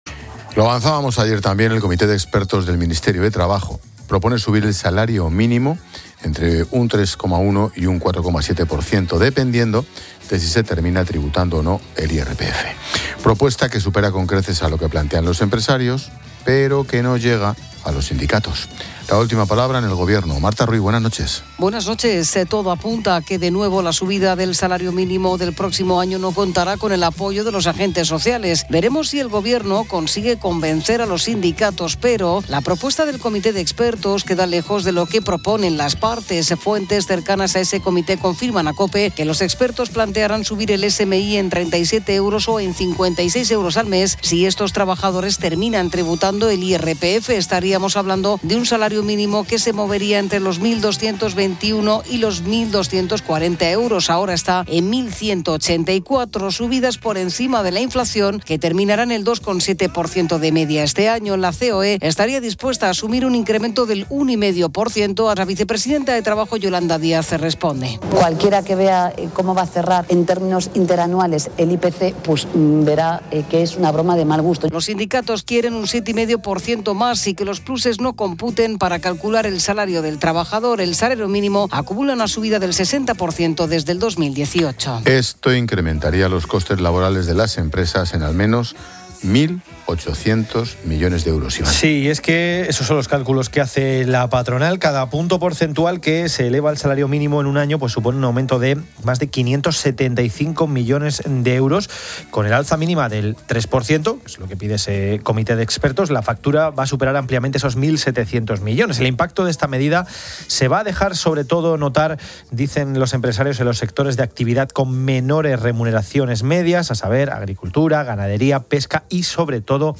Ángel Expósito y el periodista económico